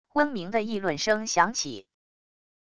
嗡鸣的议论声响起wav音频